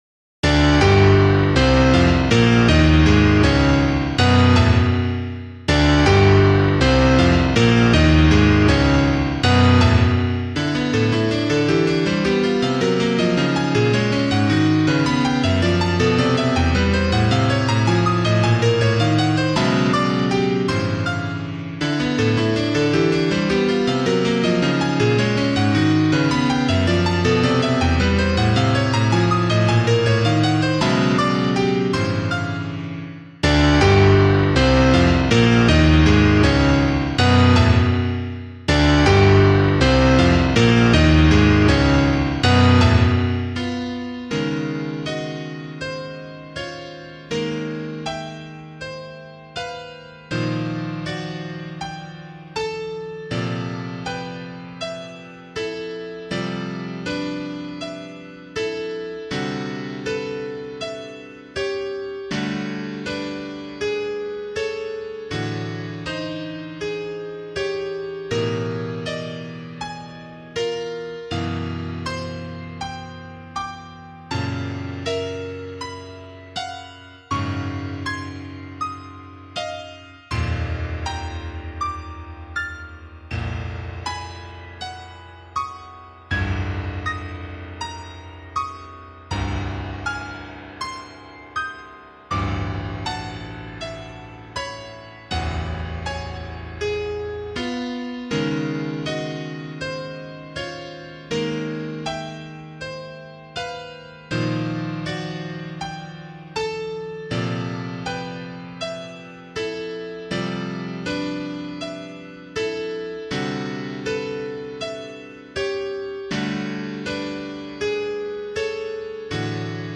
Episodes #1 - Piano Music, Solo Keyboard - Young Composers Music Forum
This work uses a tone row built upon intervals of the 4th.